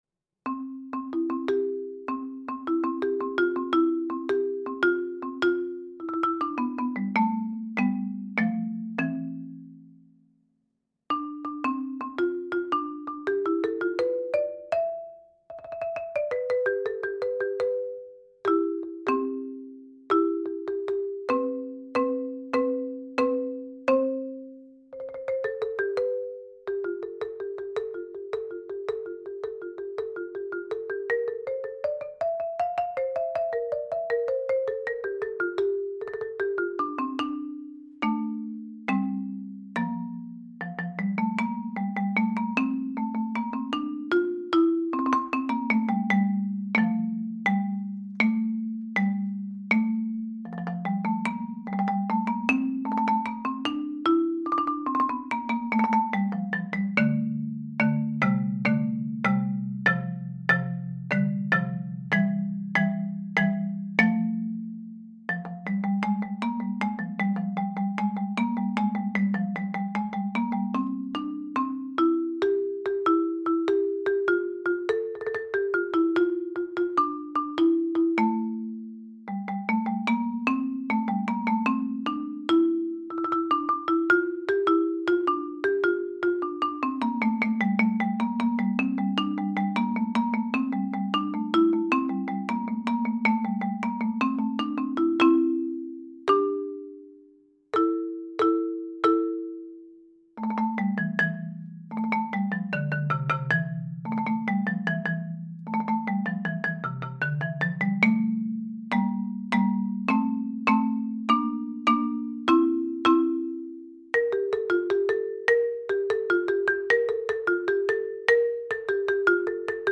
Any other instruments are triggered from my mallet controller via MIDI.
Excursion on a Cold Afternoon  This is a rapidly composed in real-time piece performed on marimba.